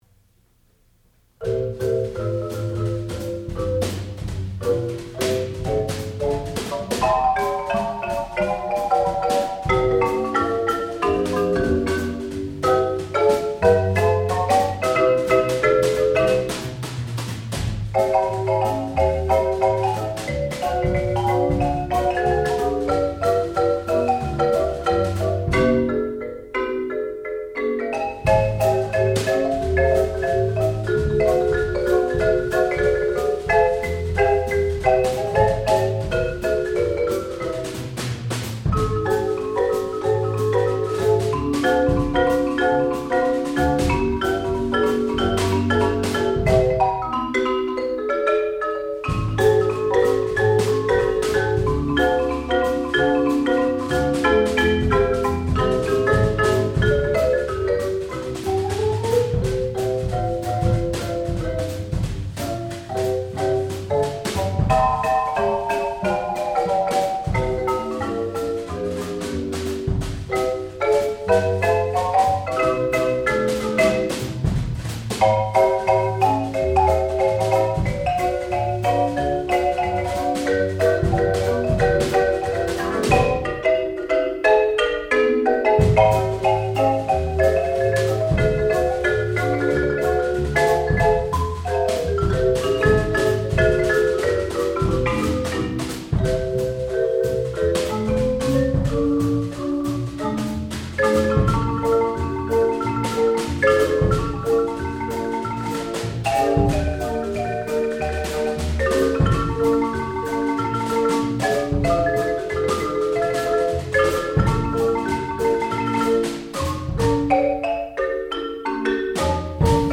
sound recording-musical
classical music
percussion
marimba
Master's Recital